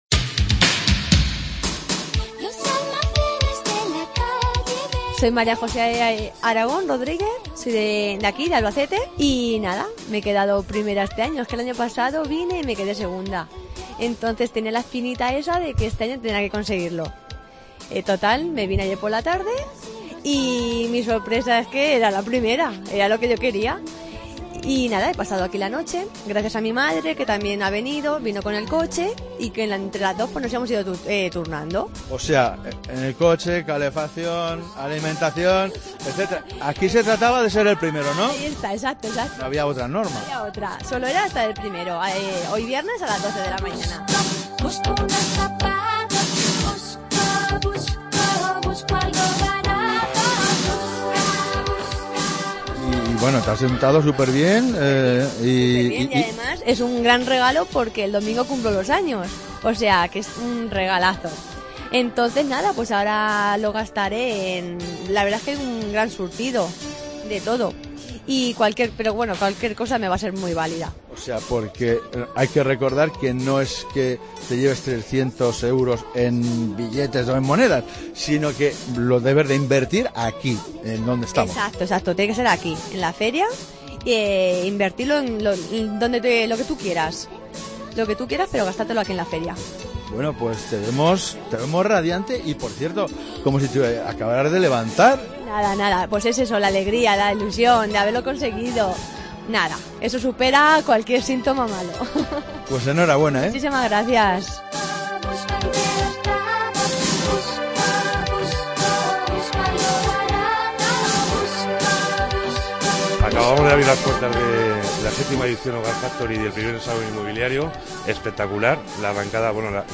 161216 Reportaje Hogar Factory
En la séptima edición de Hogar Factory, COPE es emisora oficial y durante todo el fin de semana estaremos en el Palacio de Congresos en la mayor feria de hogar de Castilla La Mancha.